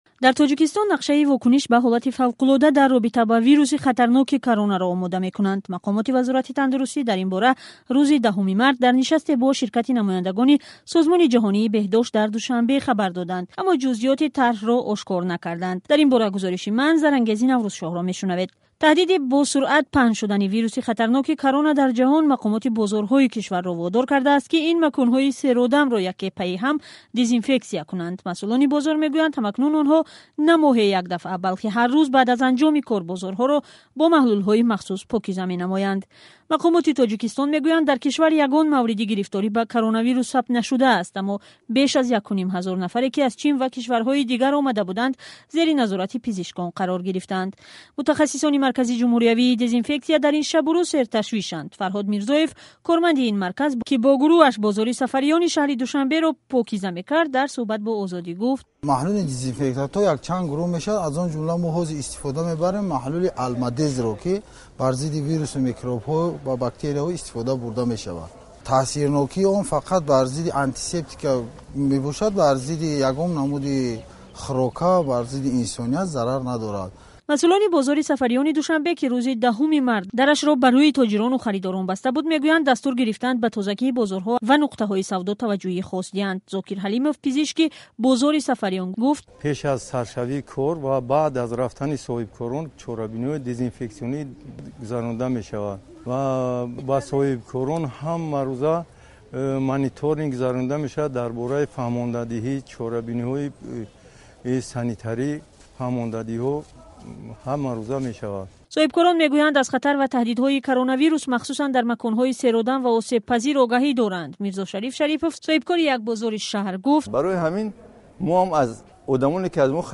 Гузоришҳои радиоӣ